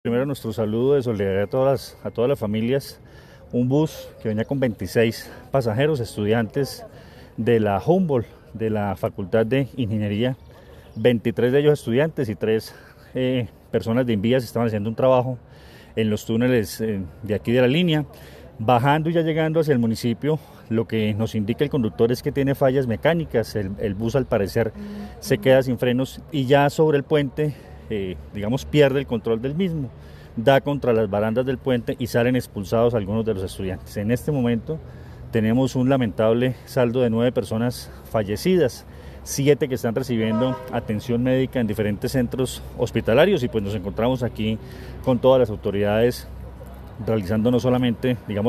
El coronel Luis Fernando Atuesta, comandante de la Policía del Quindío, entregó el reporte sobre el lamentable accidente ocurrido en el puente Helicoidal, en el sentido Alto de La Línea – Calarcá, donde nueve personas perdieron la vida.